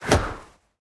throw-projectile-3.ogg